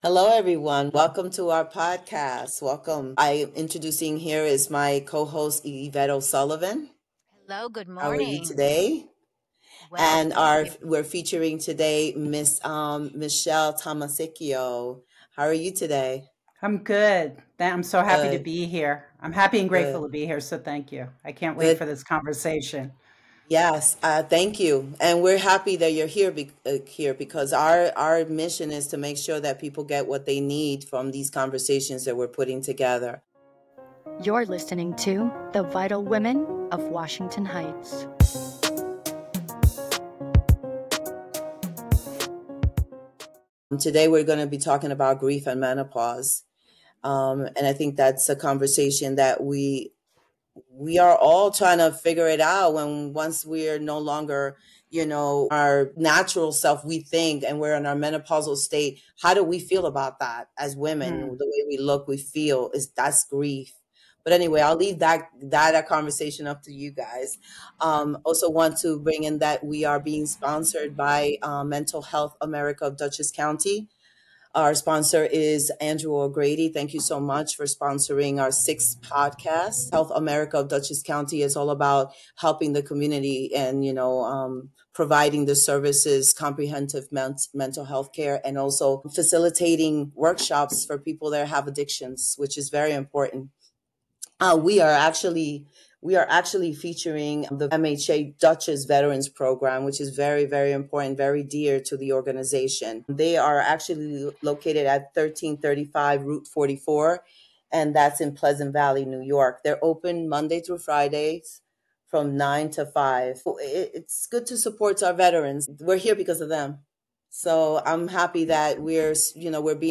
The three have an inspiring conversation about mind, body and spirit connection, navigating the menopausal changes, rebirth and self discovery and much more.